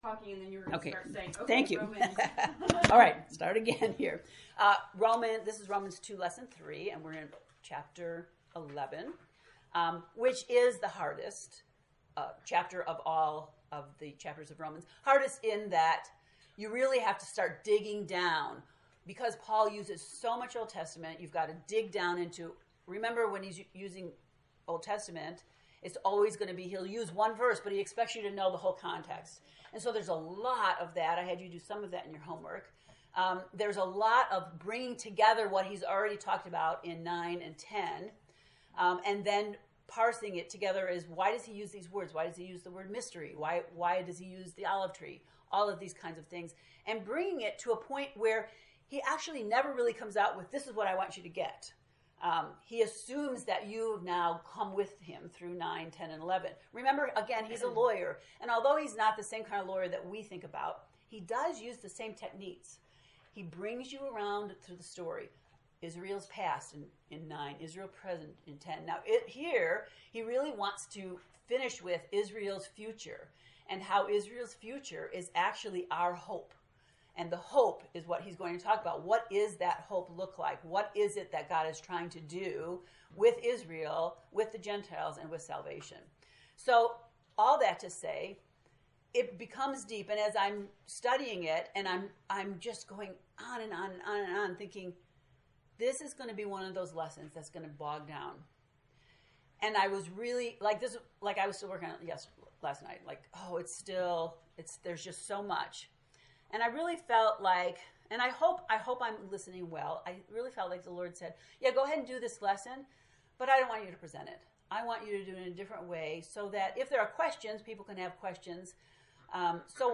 To listen to the lesson 3 lecture, “The Gang’s All Here,” click below: